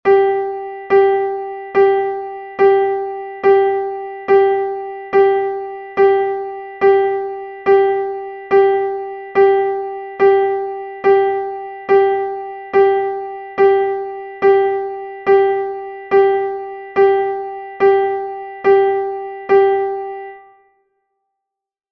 adagio.mp3